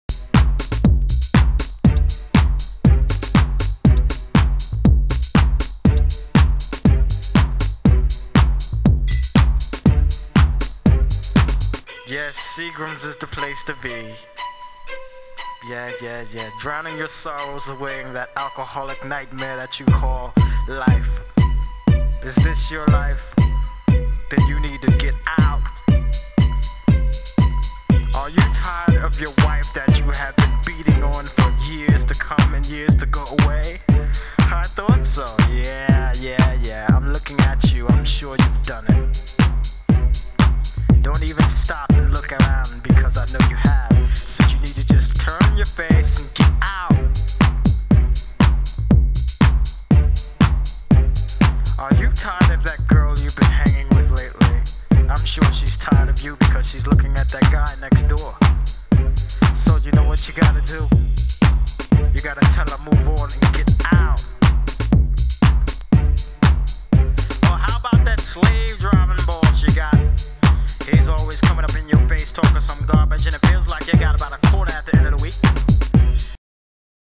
HOUSE MUSIC
盤質：小傷、チリパチノイズ有/盤の端に少し歪みあり（A1/B1の試聴箇所になっています）